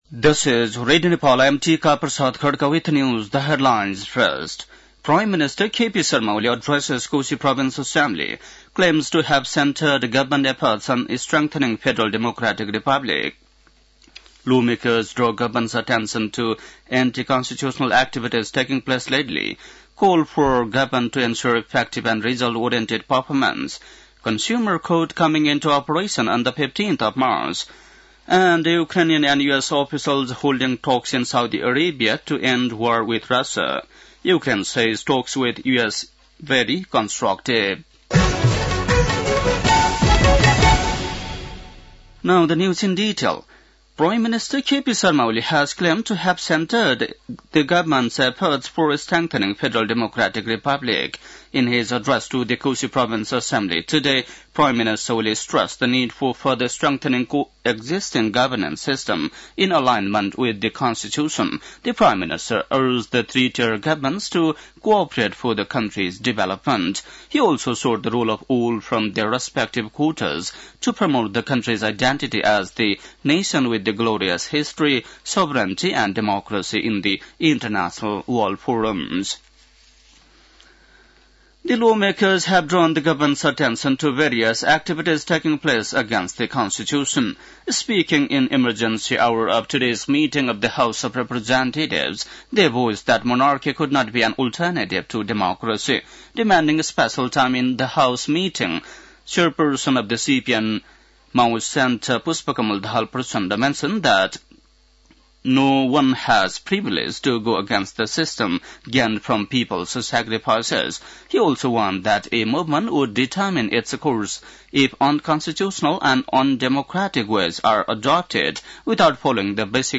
बेलुकी ८ बजेको अङ्ग्रेजी समाचार : २८ फागुन , २०८१